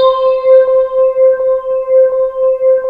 SWEEP   C4-L.wav